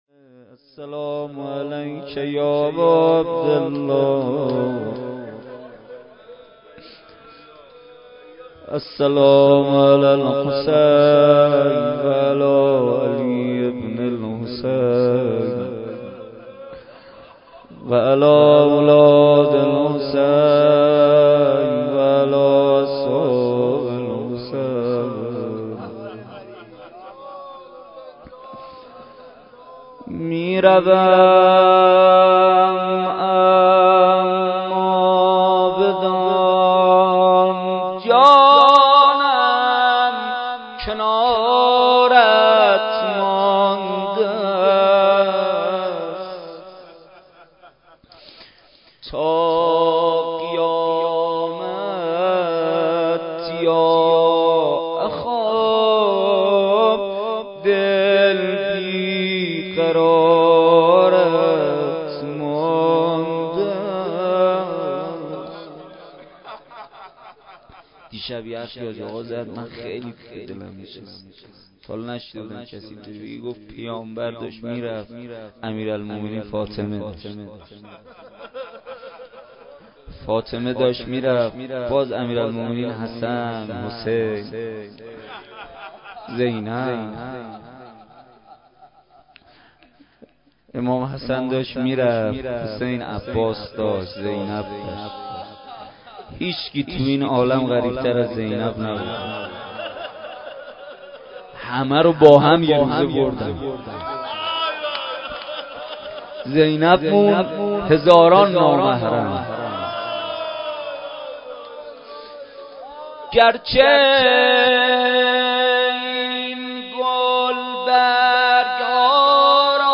شب یازدهم ماه رمضان با مداحی کربلایی محمدحسین پویانفر در ولنجک – بلوار دانشجو – کهف الشهداء برگزار گردید.
دعا ومناجات روضه لینک کپی شد گزارش خطا پسندها 0 اشتراک گذاری فیسبوک سروش واتس‌اپ لینکدین توییتر تلگرام اشتراک گذاری فیسبوک سروش واتس‌اپ لینکدین توییتر تلگرام